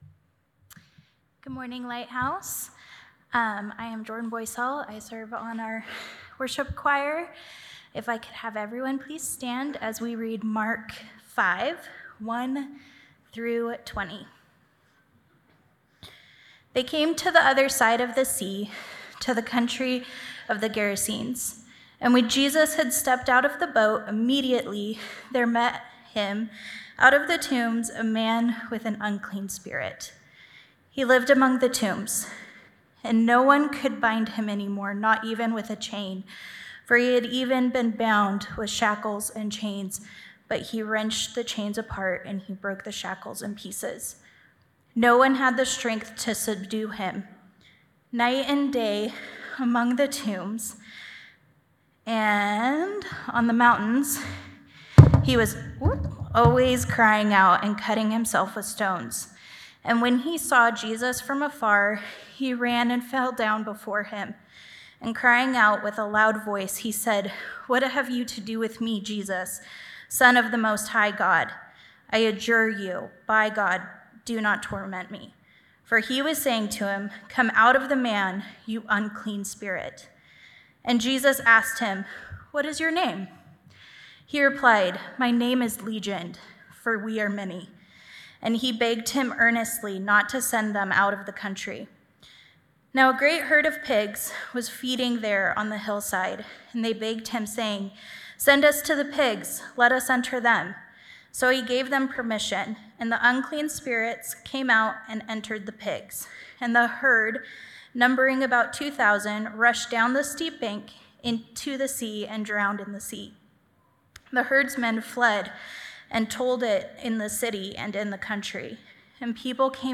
Lighthouse Church sermon podcasts